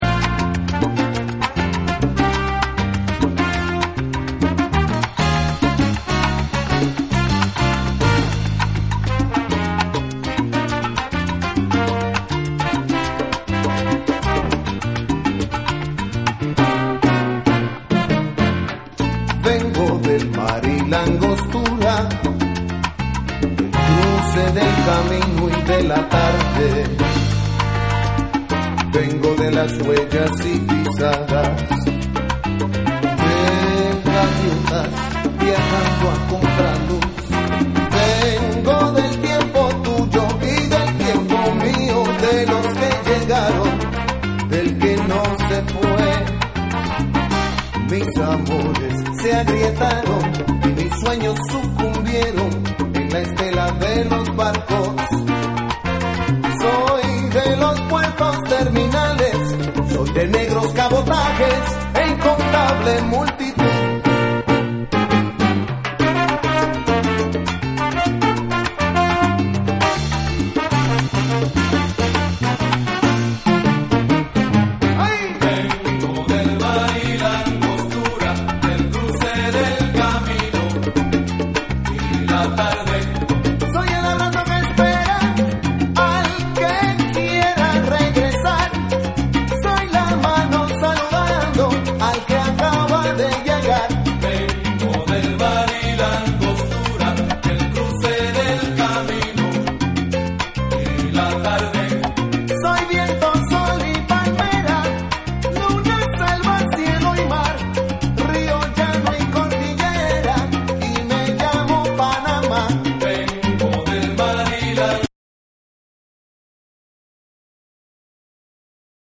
WORLD / LATIN